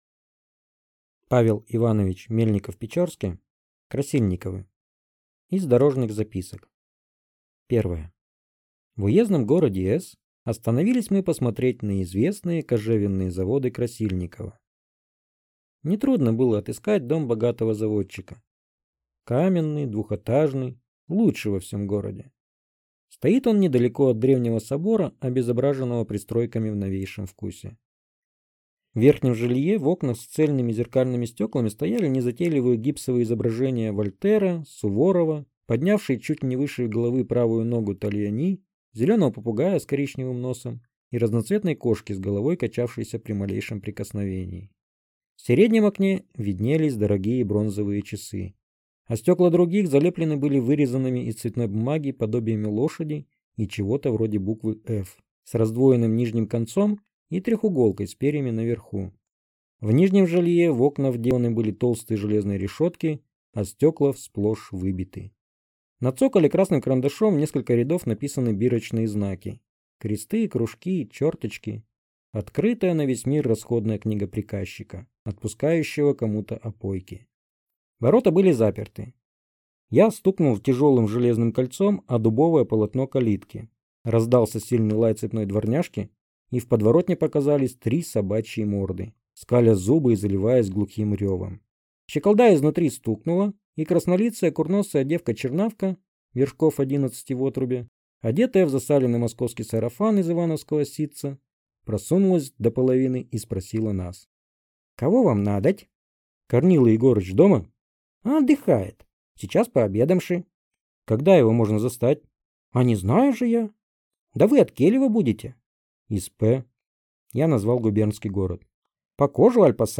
Аудиокнига Красильниковы | Библиотека аудиокниг